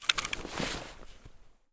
inv_open.ogg